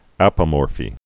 (ăpə-môrfē)